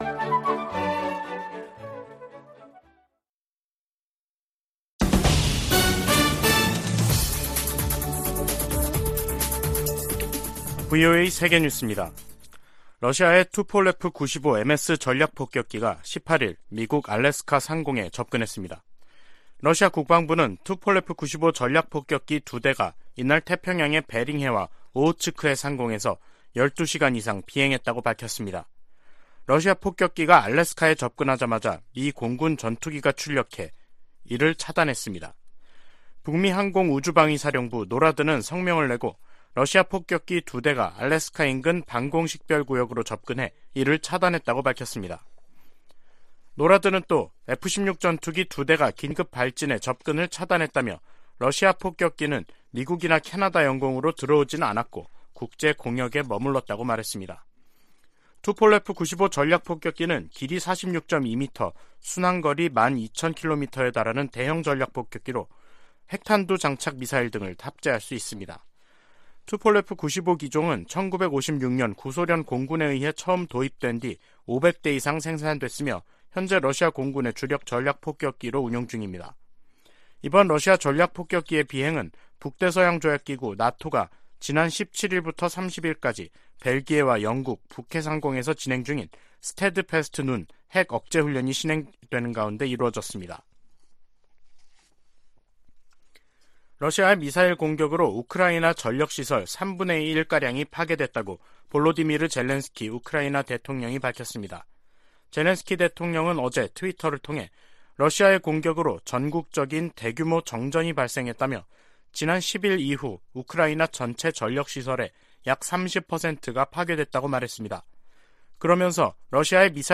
VOA 한국어 간판 뉴스 프로그램 '뉴스 투데이', 2022년 10월 19일 2부 방송입니다. 북한이 18일 밤부터 19일 오후까지 동해와 서해 완충구역으로 350여 발의 포병 사격을 가하면서 또 다시 9.19 남북군사합의를 위반했습니다. 미 국무부는 북한의 포 사격에 대해 모든 도발적 행동을 중단할 것을 촉구했습니다. 미국 헤리티지재단은 '2023 미국 군사력 지수' 보고서에서 북한의 핵을 가장 큰 군사적 위협 중 하나로 꼽았습니다.